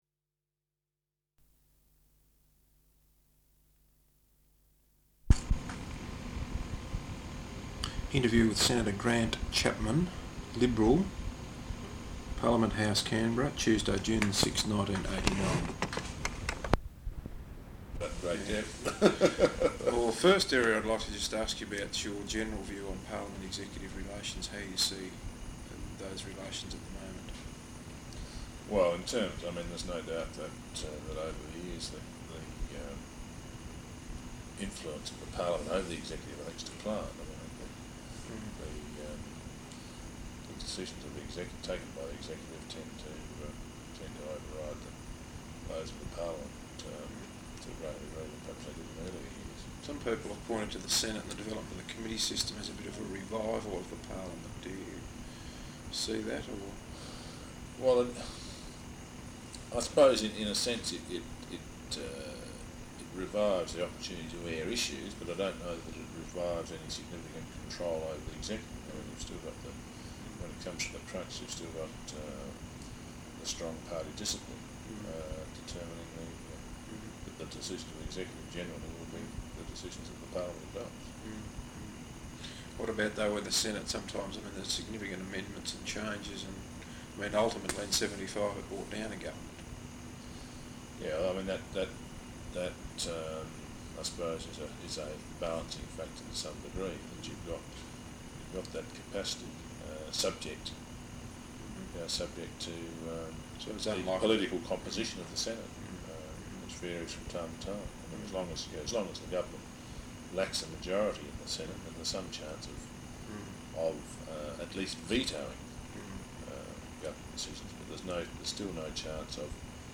Interview with Senator Grant Chapman, Liberal Senator for South Australia. Parliament House, Canberra, June 6th 1989.